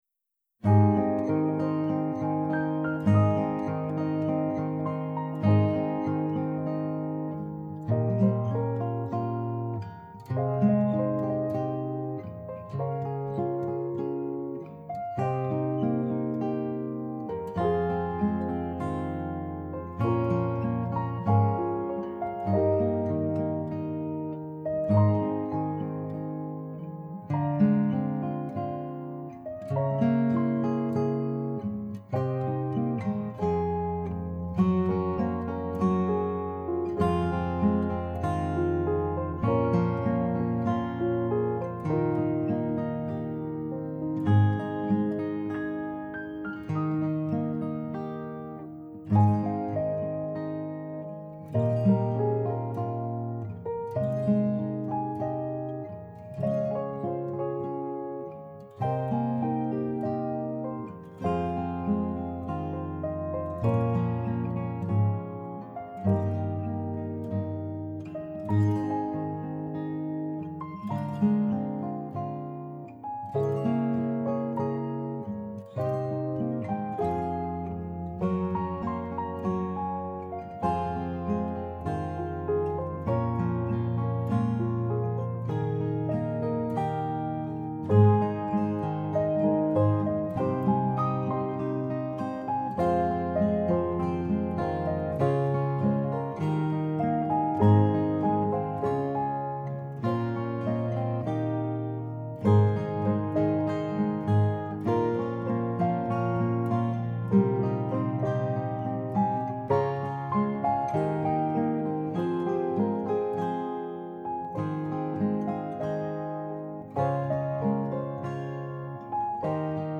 Rainbow Through My Tears Guitar Mix 2025